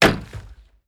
Chopping wood 6.wav